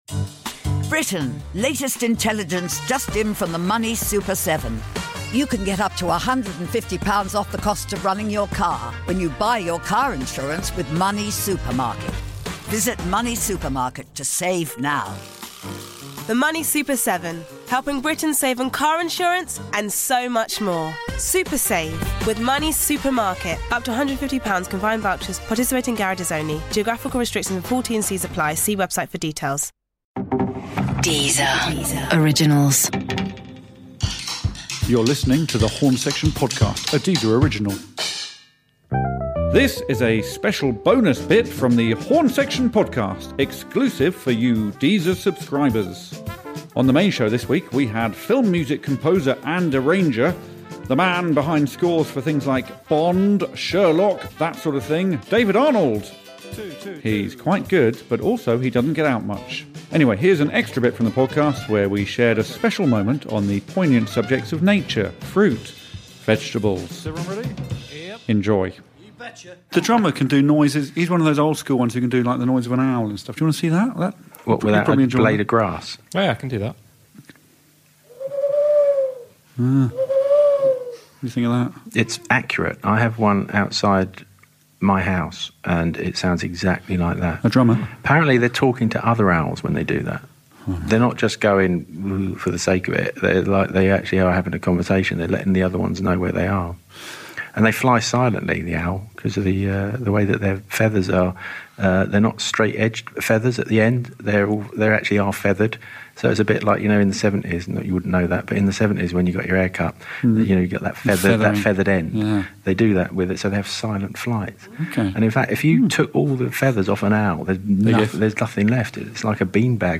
Today we talk nature with composer and producer David Arnold, who has some interesting facts about owls. Also featuring a song about tomatoes.